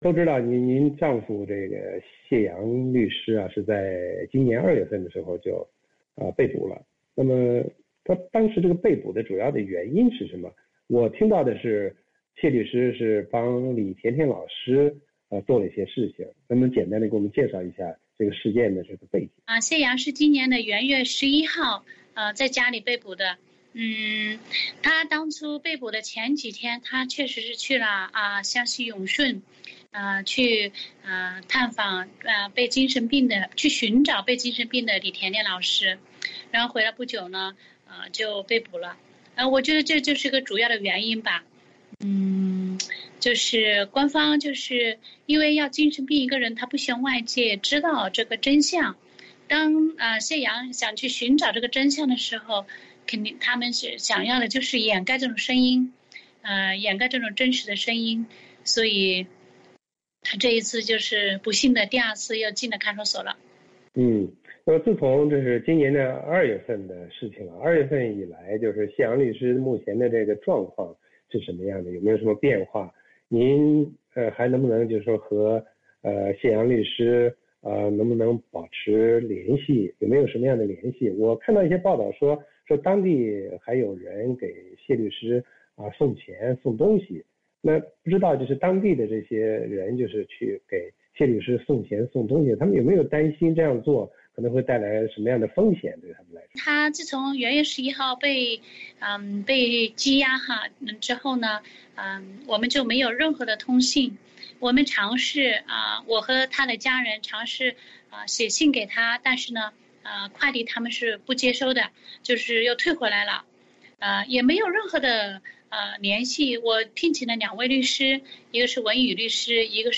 VOA专访: 维权人士谈中国人权律师现状